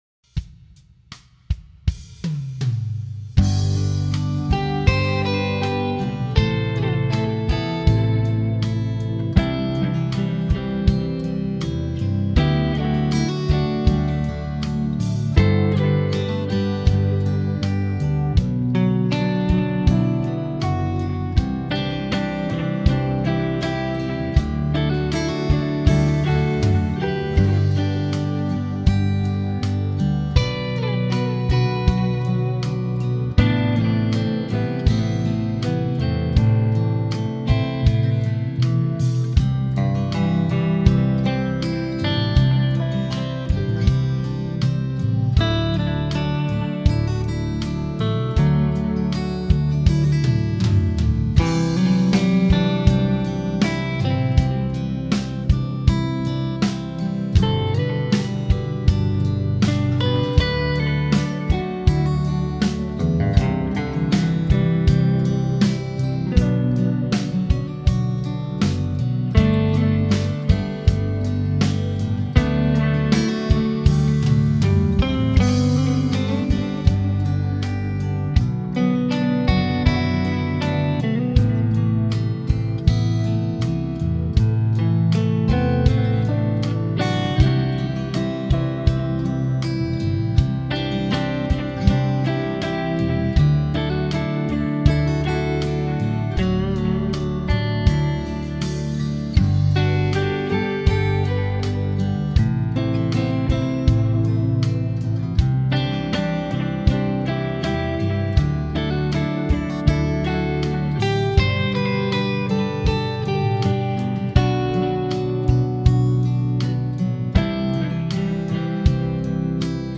Home > Music > Blues > Bright > Smooth > Medium